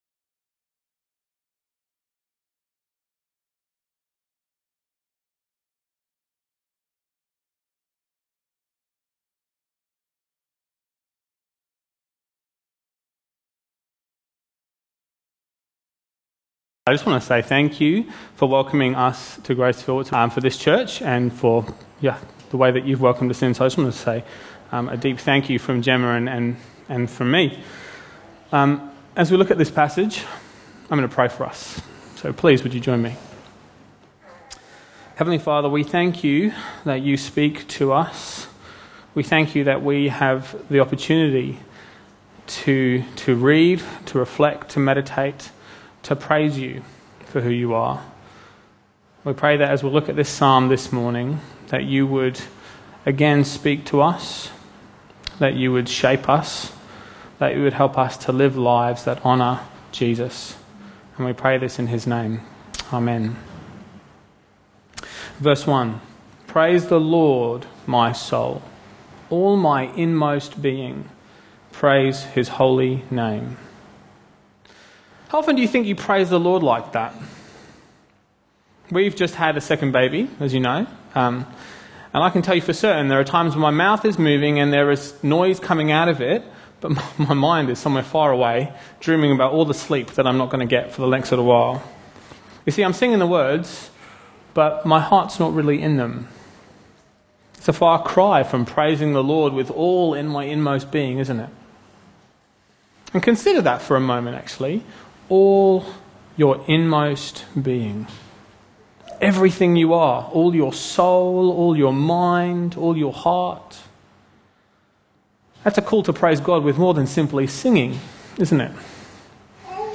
Bible Talks Bible Reading: Psalm 103